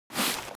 pickup.ogg